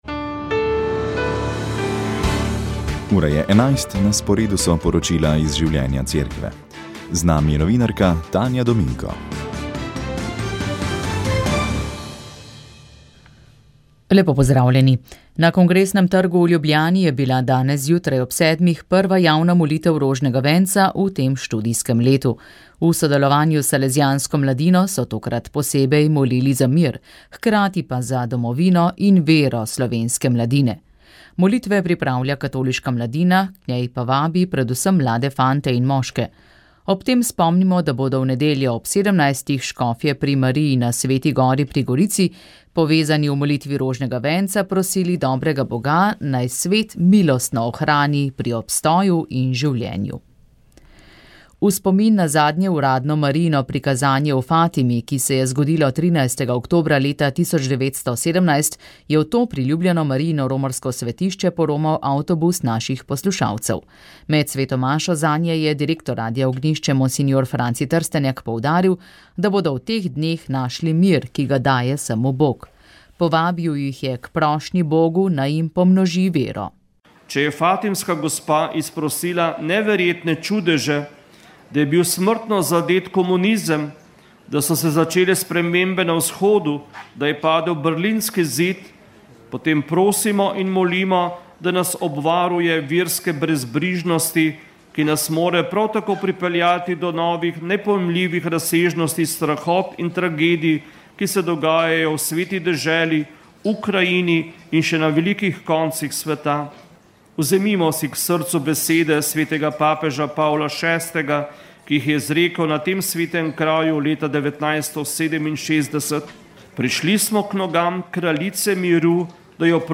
Radio Ognjišče info novice Informativne oddaje VEČ ...